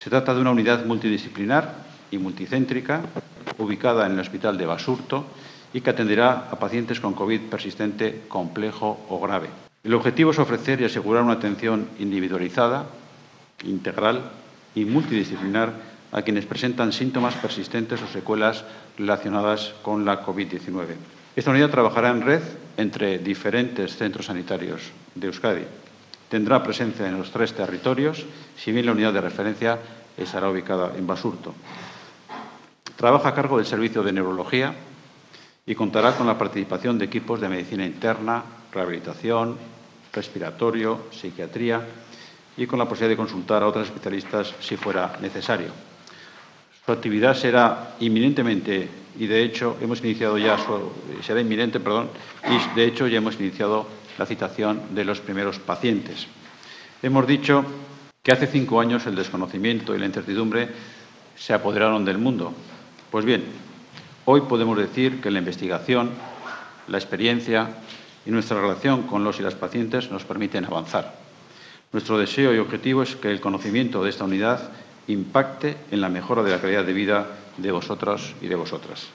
El consejero de Salud ha presentado hoy el nuevo servicio sanitario
22.-CONSEJERO-LONG-COVID.mp3